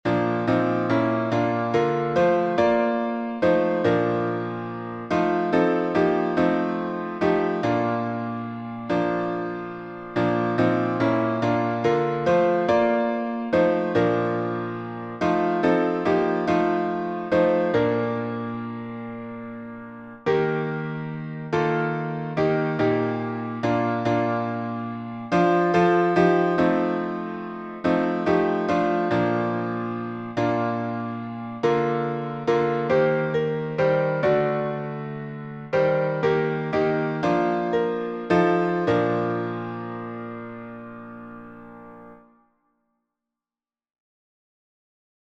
Words by Civilla D. Martin (1866–1948)Tune: GOD CARES by W. Stillman Martin (1862-1935)Key signature: B flat major (2 flats)Time signature: 6/8Meter: 8.6.8.6. (C.M.) with RefrainPublic Domain1.